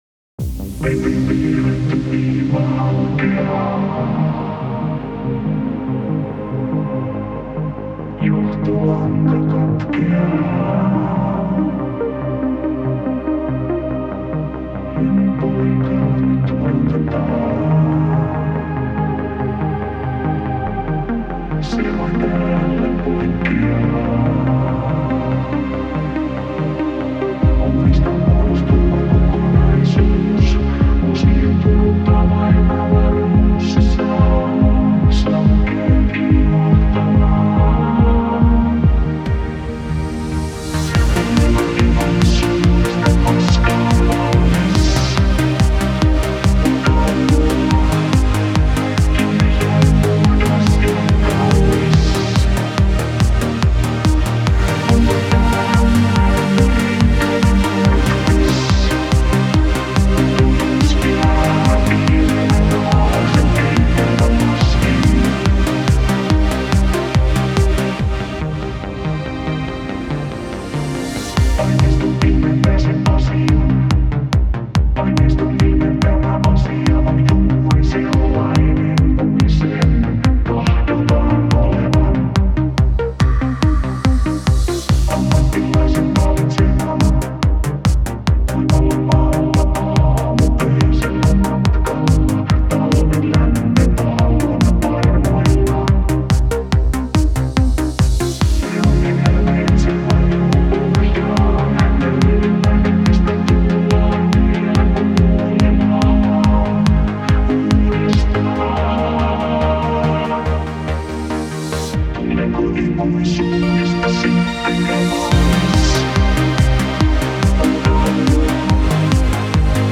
Kompo Musiikki